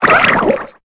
Cri de Nucléos dans Pokémon Épée et Bouclier.